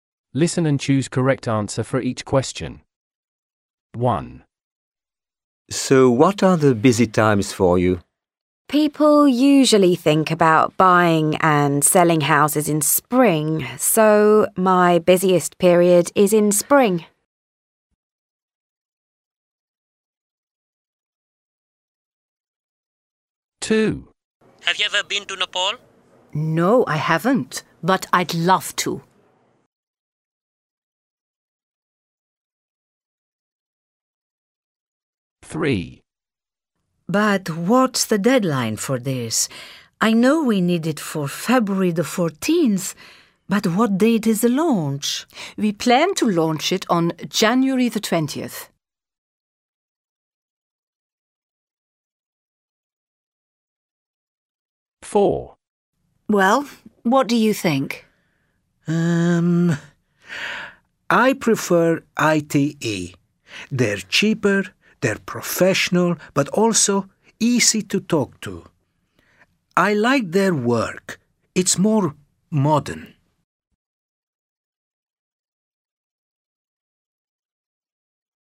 Conversation 1: When is the woman's busiest time? =>  18.
Conversation 4: Why does the man like ITE?